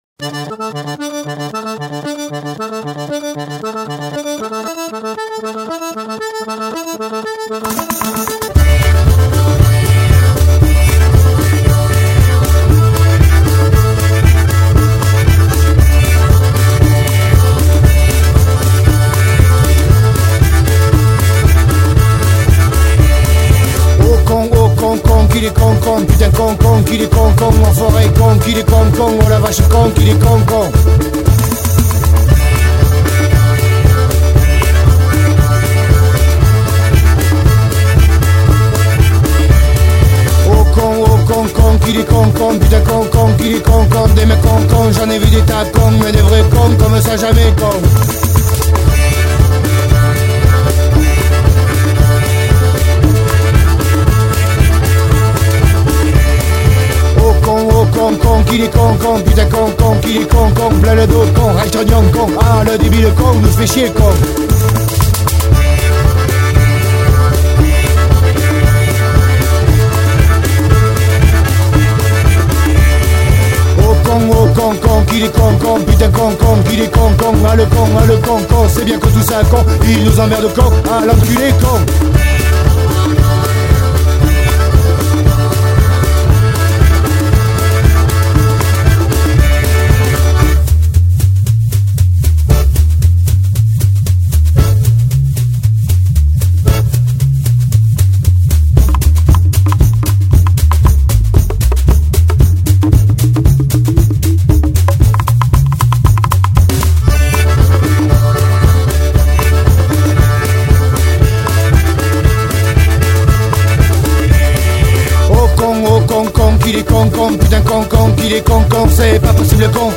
Une version qui permet de se faire son karaoké...